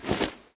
ui_interface_157.wav